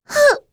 s023_Noraml_Hit.wav